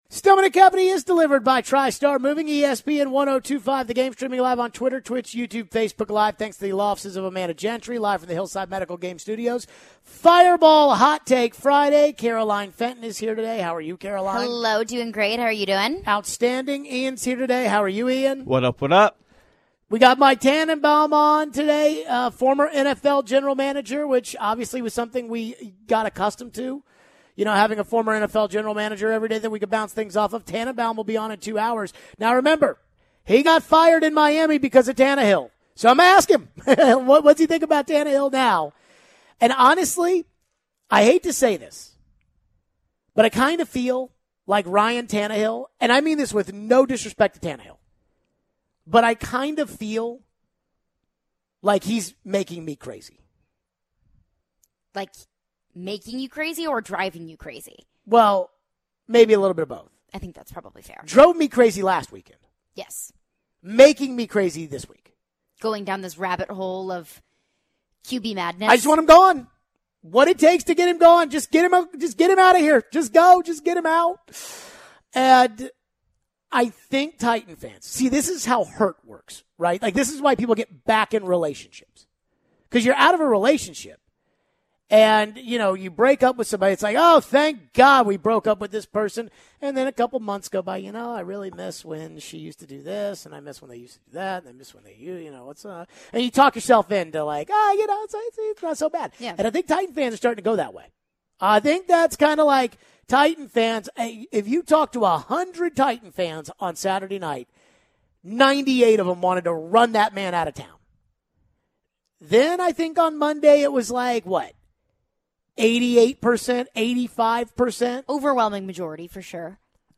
We take your phones on the Titans and Tannehill.